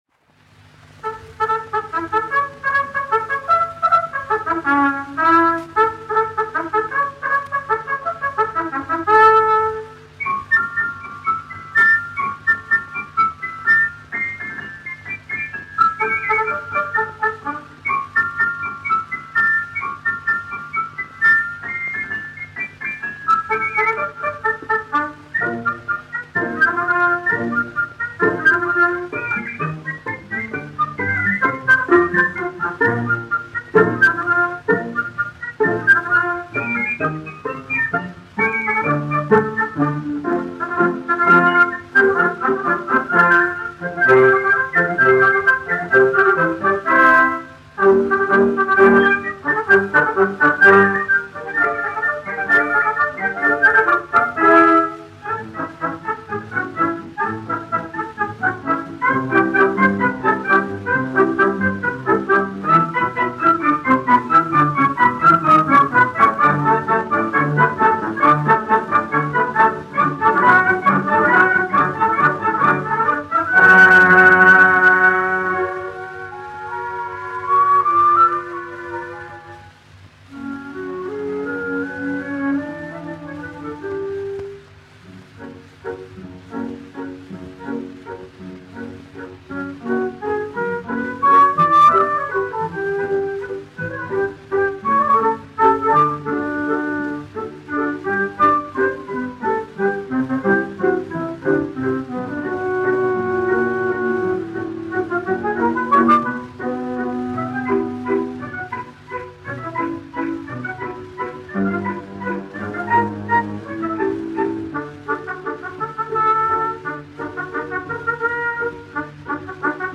1 skpl. : analogs, 78 apgr/min, mono ; 25 cm
Operas--Fragmenti, aranžēti
Skaņuplate